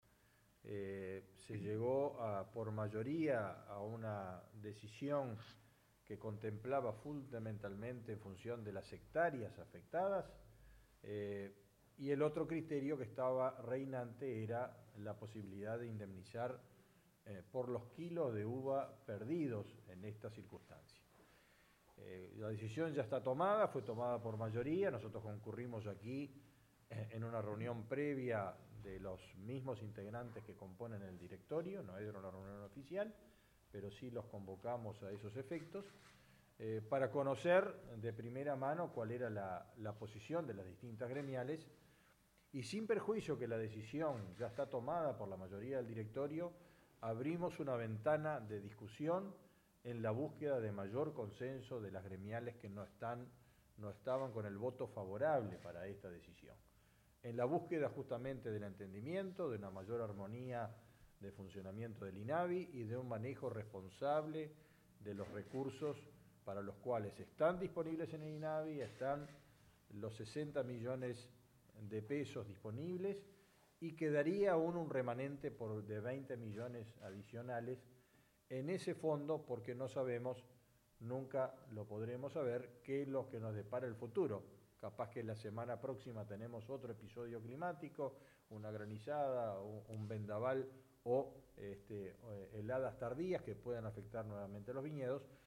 Fernando-Mattos-2.mp3